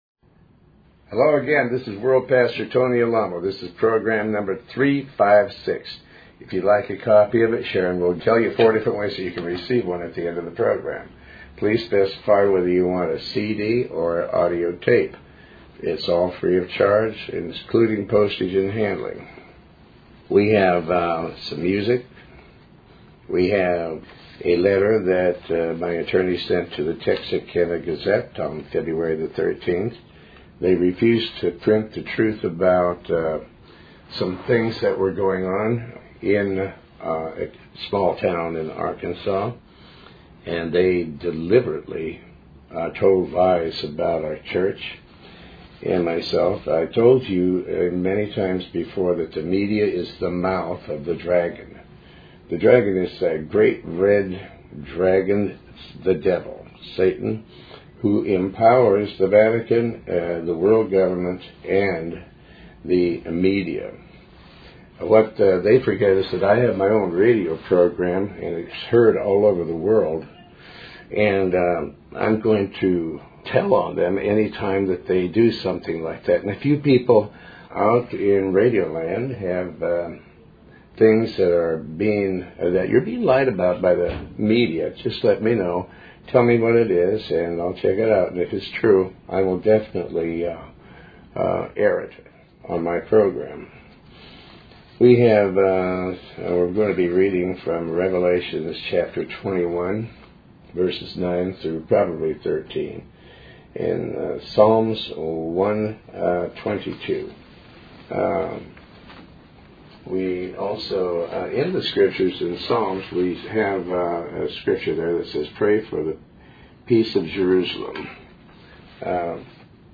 Talk Show Episode, Audio Podcast, Tony Alamo and Program 356 on , show guests , about pastor tony alamo,Tony Alamo Christian Ministries,Faith, categorized as Health & Lifestyle,History,Love & Relationships,Philosophy,Psychology,Christianity,Inspirational,Motivational,Society and Culture